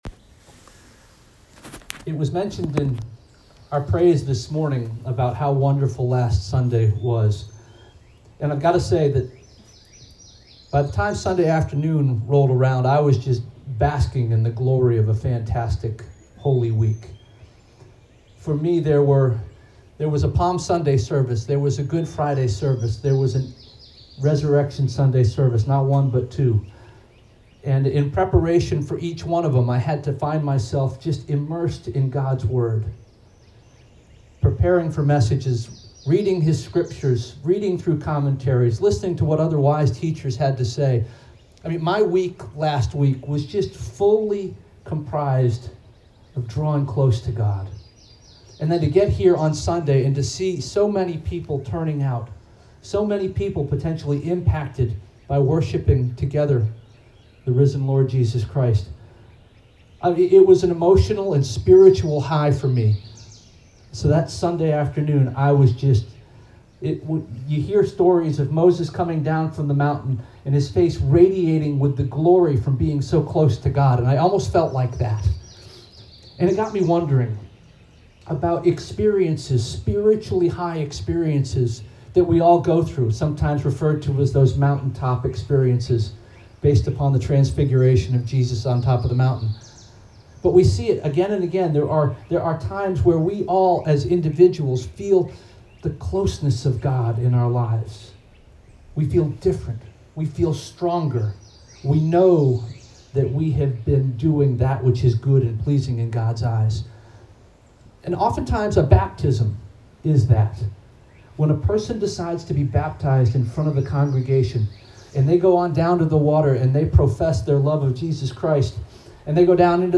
April 11 2021 Sermon